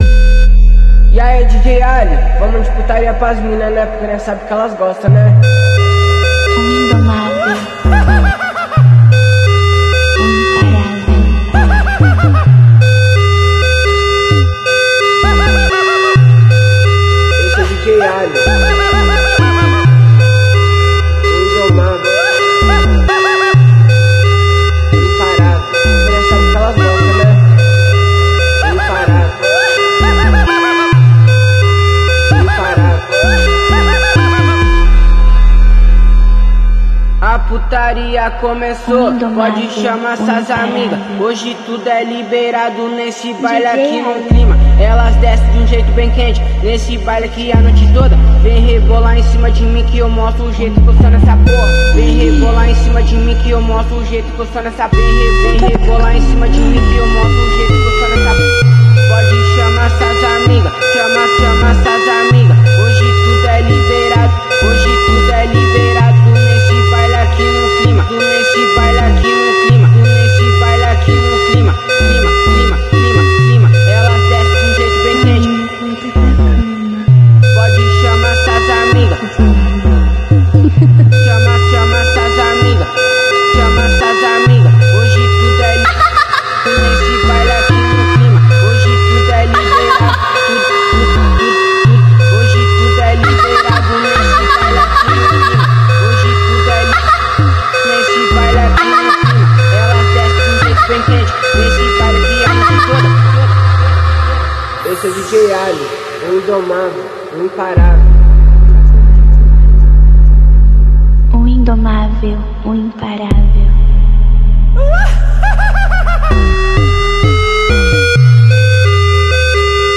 Subaru impreza wrx do filme